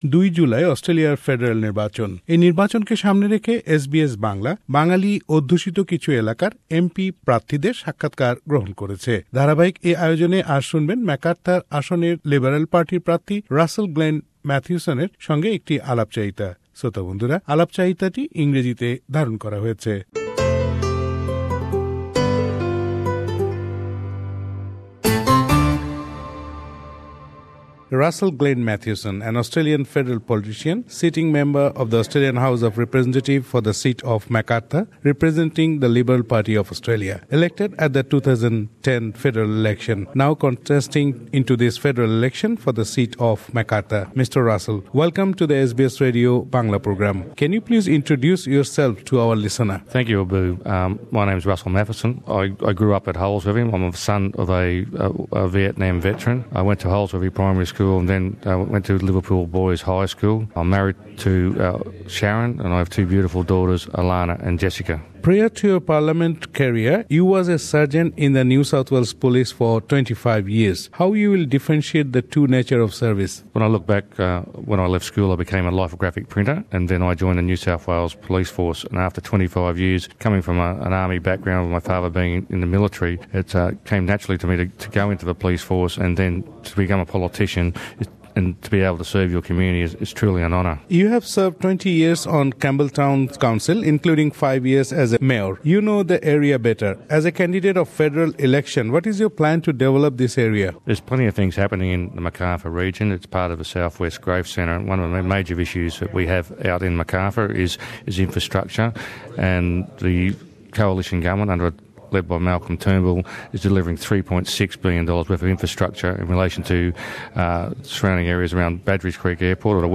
Federal Election 2016 : interview with Russell Matheson MP candidate for Macarthur